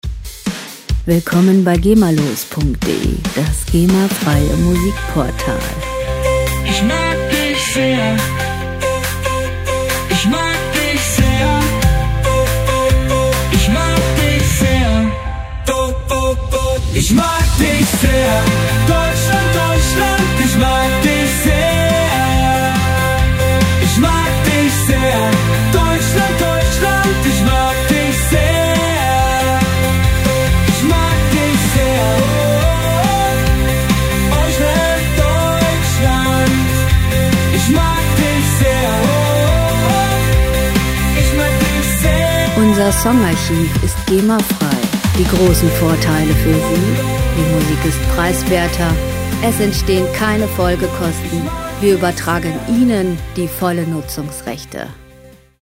Pop Musik aus der Rubrik: "Popwelt Deutsch"
Musikstil: Deutschrock
Tempo: 140 bpm
Tonart: C-Dur
Charakter: mitreißend, eingängig
Instrumentierung: Piano, E-Gitarre, E-Bass, Sänger